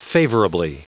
Prononciation du mot : favorably
favorably.wav